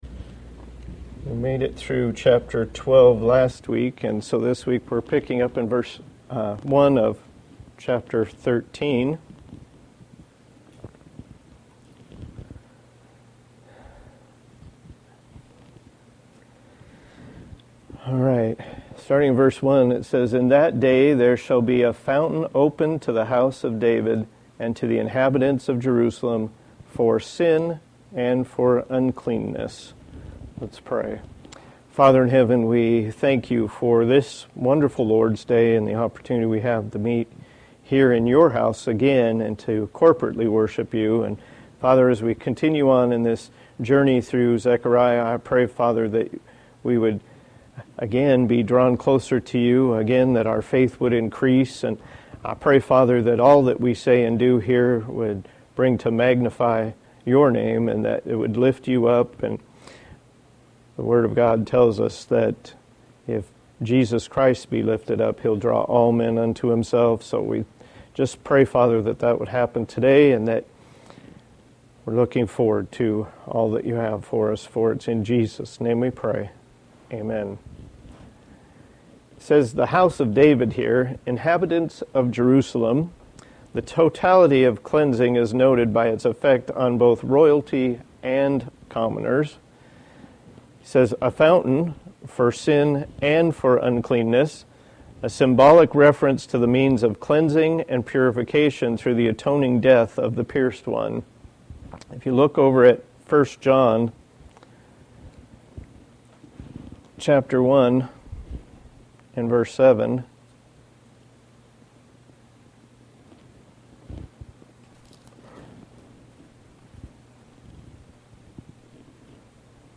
Passage: Zechariah 13:1-4 Service Type: Sunday School